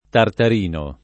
tartar&no] pers. m. — personaggio di A. Daudet (1872) — con tronc. o senza, nelle varie trad. it., il nome completo Tartarin di Tarascona [tartar&n di taraSk1na] o Tartarino di Tarascona — sim. i cogn. (it.) Tartarini, Tartarino — fr. Tartarin de Tarascon [tartar4^ d taraSk6^] — sim. il cogn. (fr.) Tartarin